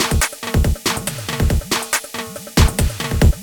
Perfect for beat, beats, drumkit.
beat beats drumkit fast Gabber hardcore House Jungle sound effect free sound royalty free Music